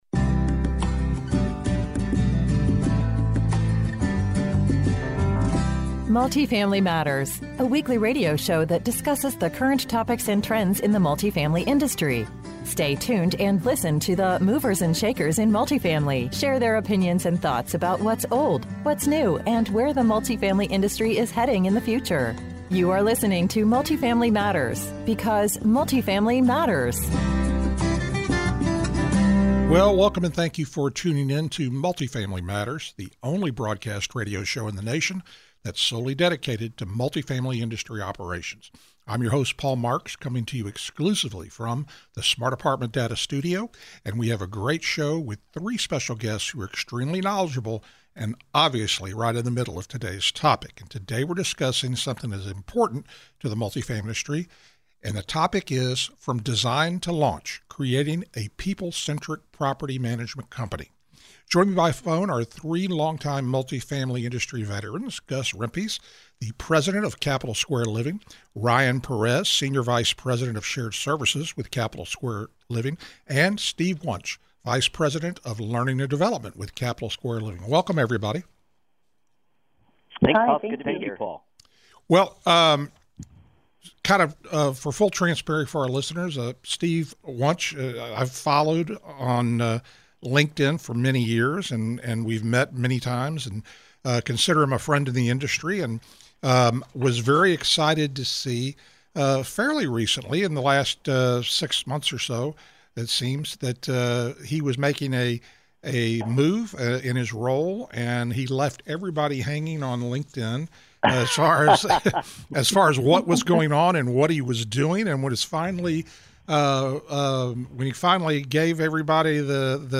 A conversation with three longtime multifamily industry veterans